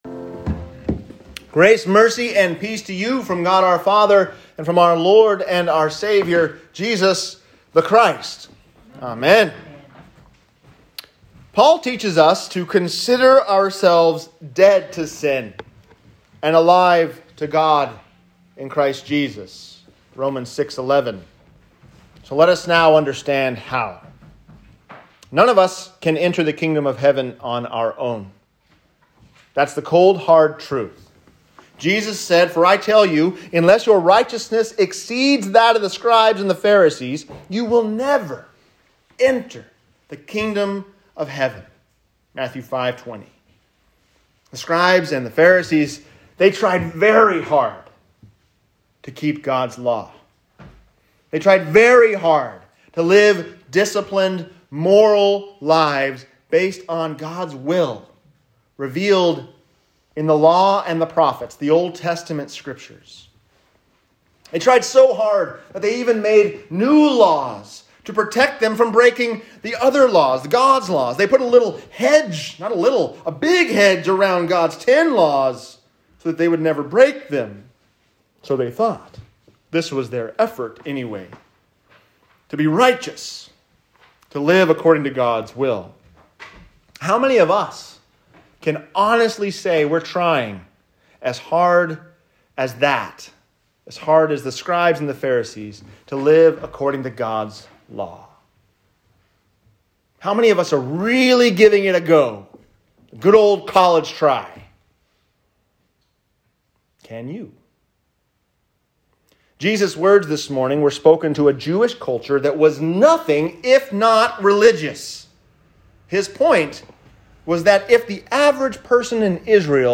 Saved By Christ’s Righteousness | Sermon